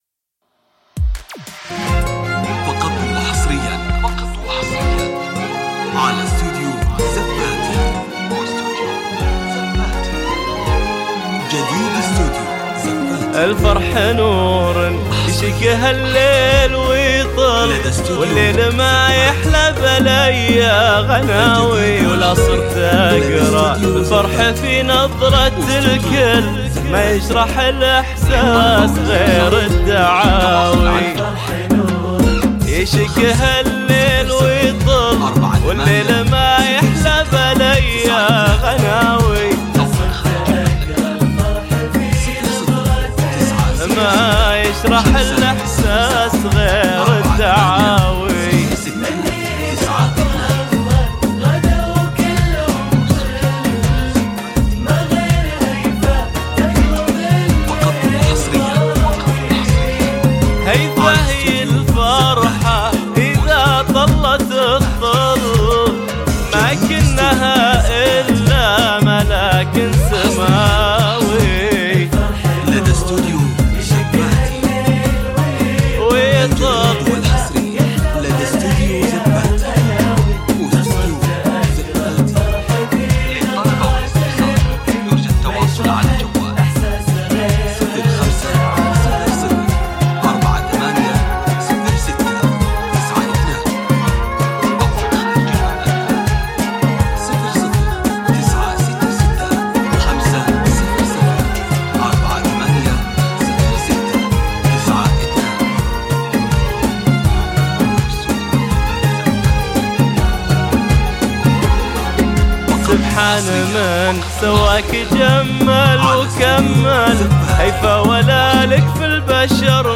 زفة كوشة – تنفيذ بالاسماء